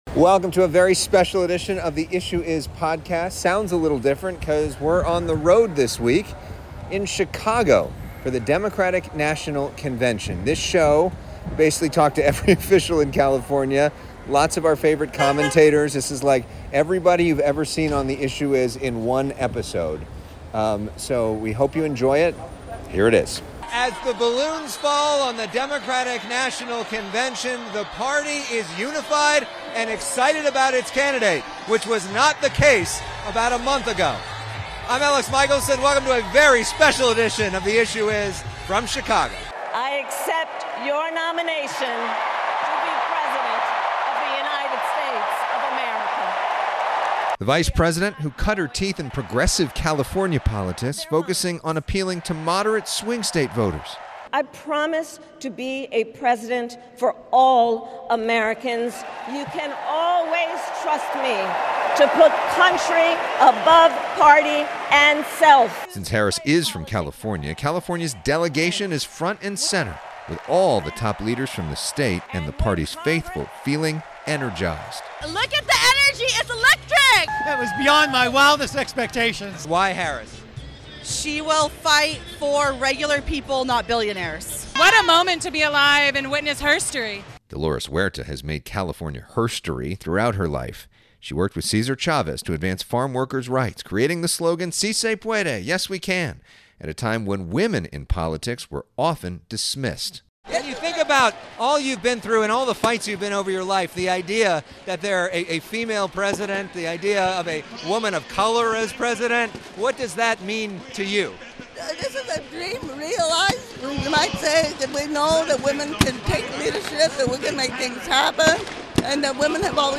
An historic week in American politics, as Vice President Kamala Harris accepted the Democratic nomination for President. "The Issue Is" was in Chicago for it all.